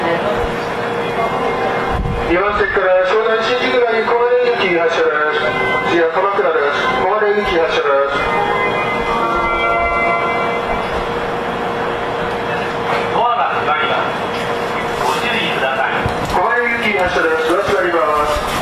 発車メロディーの音量が小さい上に駅員さんが喋りまくるので収録は困難です。